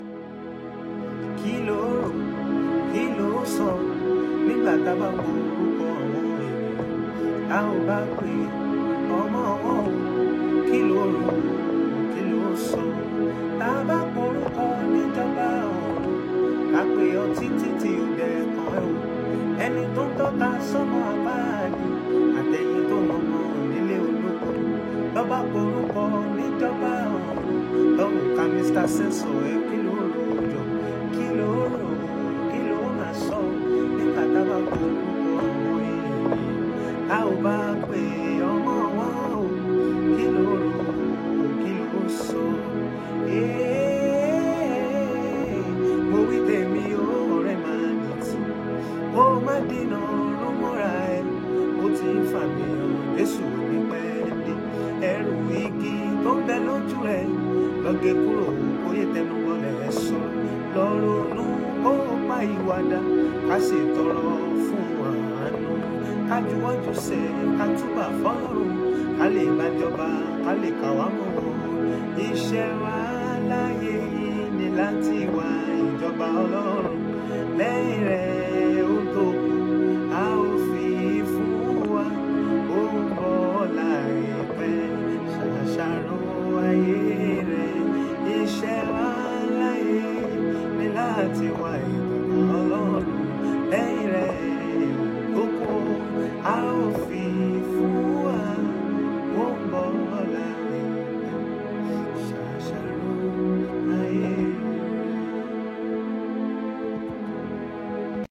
Yoruba Gospel Music